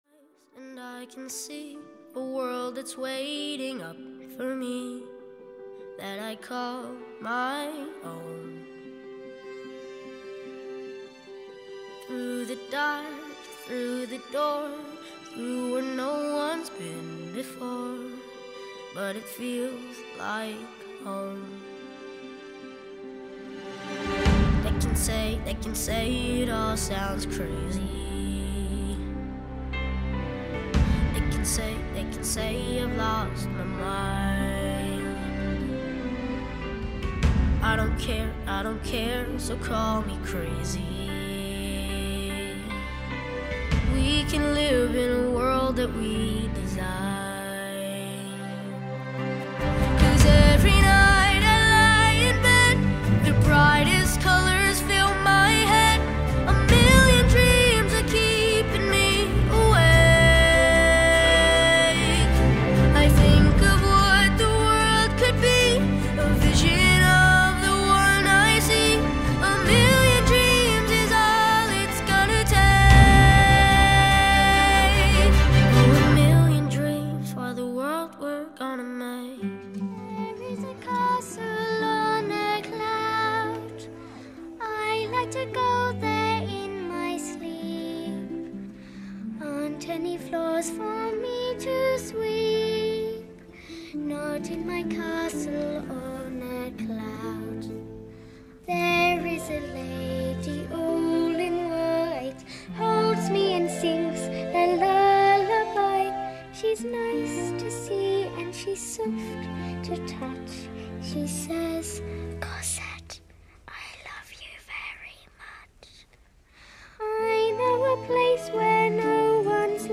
Click below to hear music for practising.